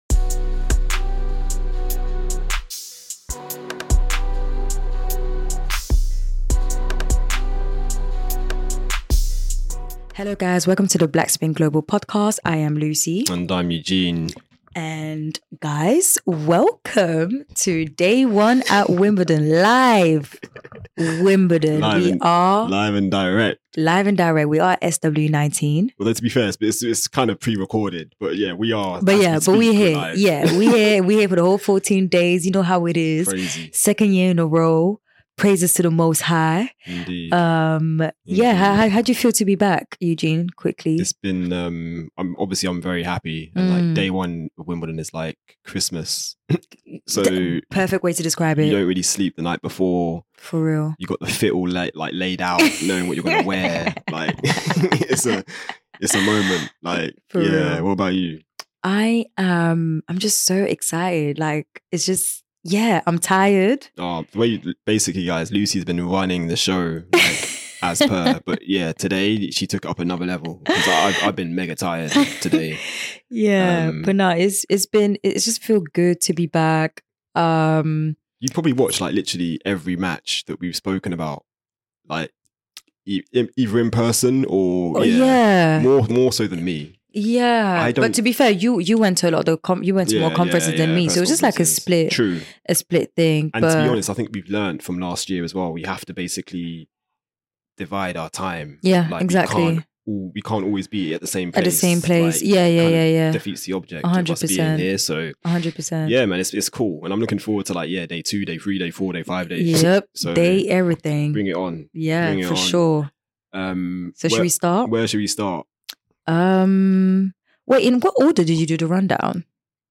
We also include interview snippets from Tiafoe, Keys, Osaka, Gauff and young qualifier Robin Montgomery.